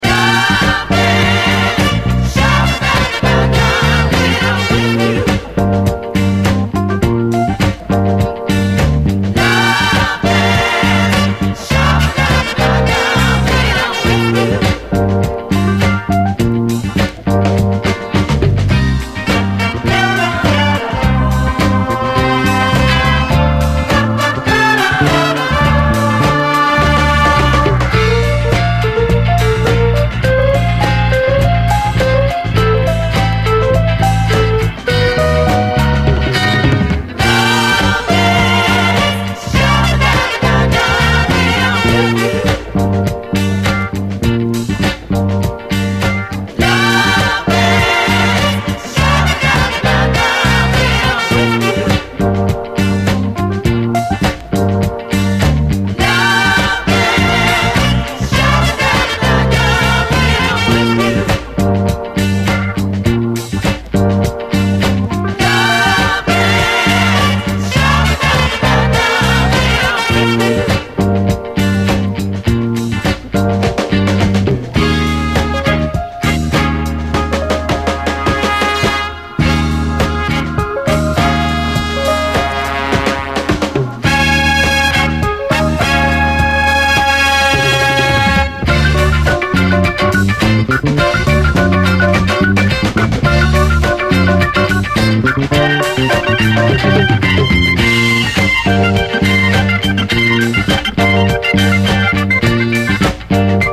SOUL, 70's～ SOUL, 7INCH
単発企画モノの思われるラウンジー・ファンク45！